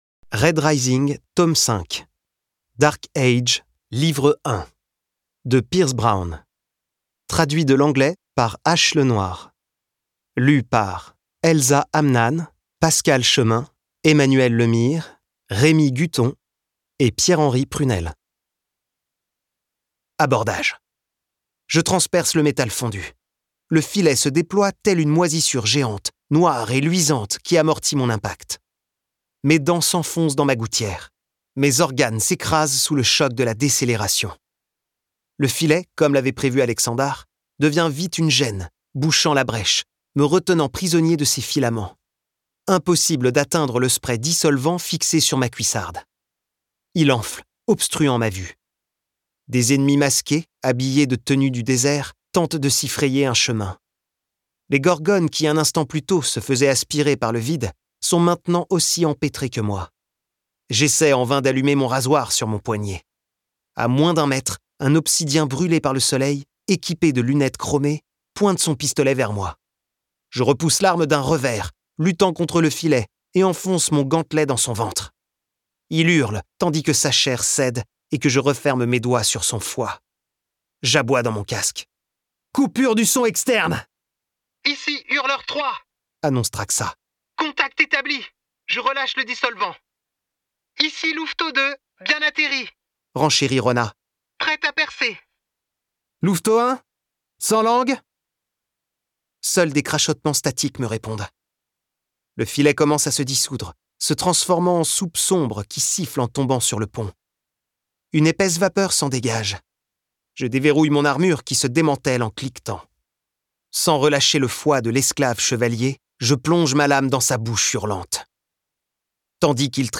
Plongez dans la suite des aventures de Darrow grâce à une narration polyphonique pleine d’énergie